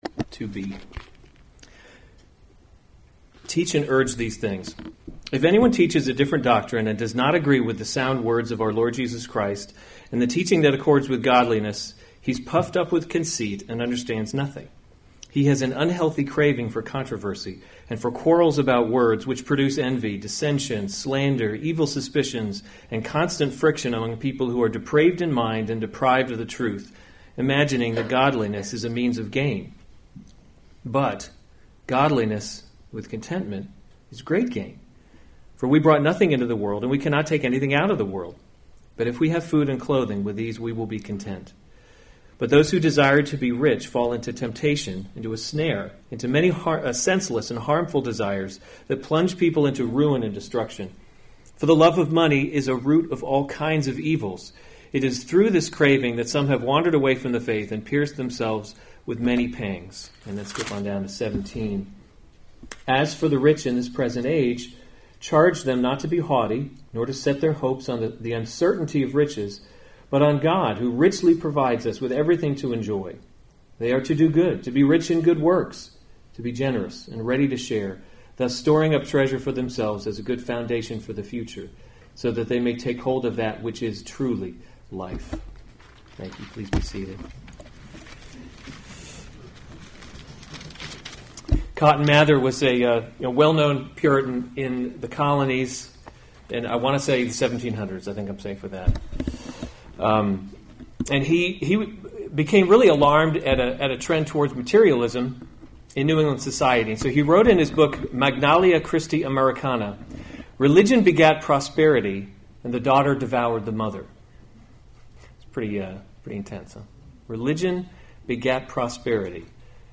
May 27, 2017 1 Timothy – Leading by Example series Weekly Sunday Service Save/Download this sermon 1 Timothy 6:2-10; 17-19 Other sermons from 1 Timothy 2 Those who have believing masters […]